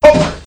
Worms speechbanks
jump1.wav